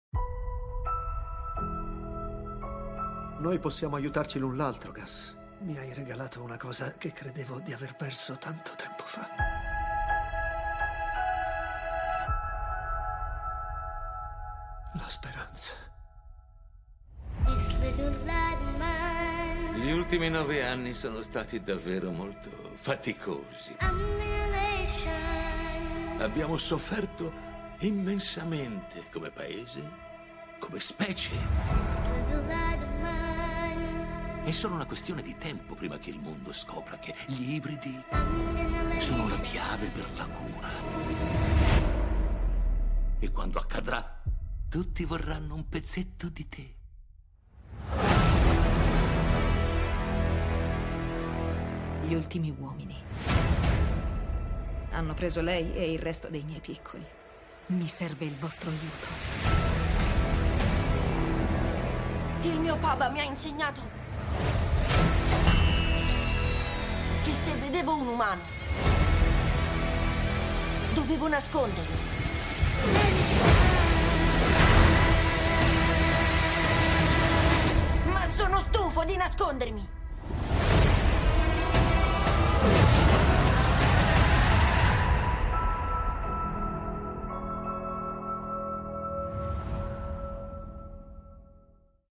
Trailer in italiano (Stagione 2 Teaser)